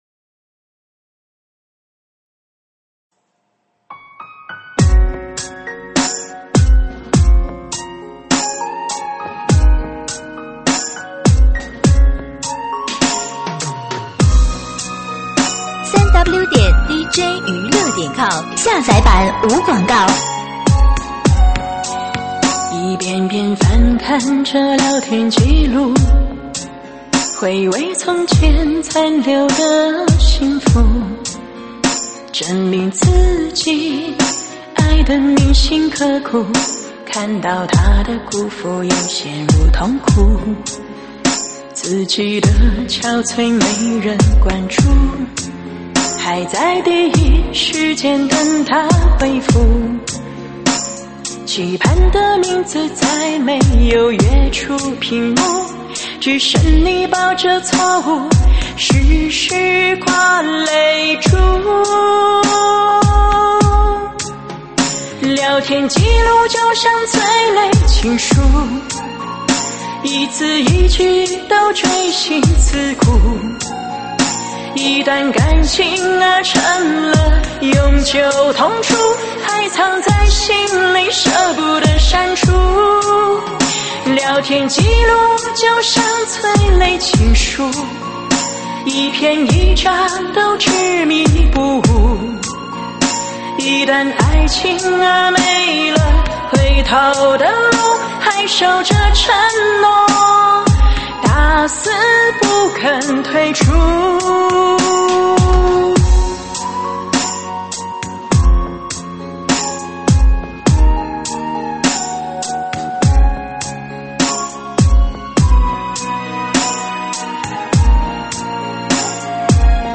舞曲类别：伤感情歌